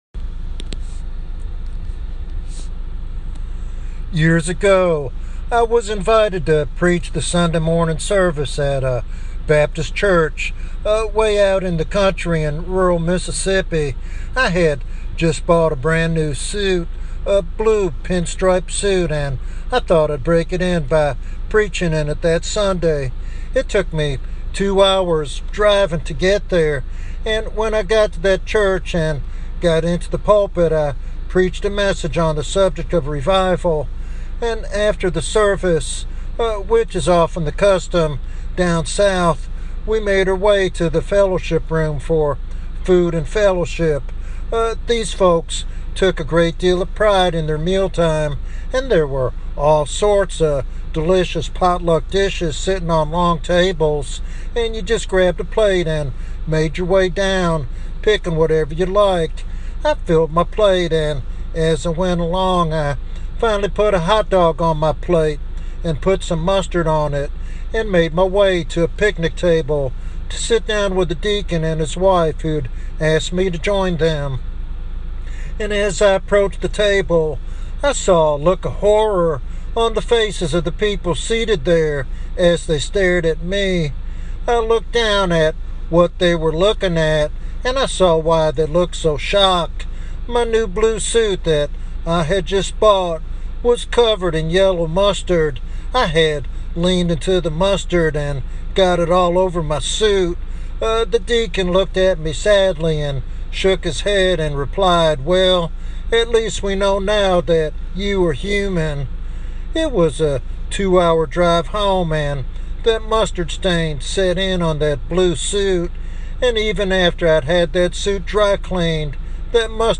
This devotional sermon calls Christians to be authentic witnesses whose lives make a lasting impact for Christ.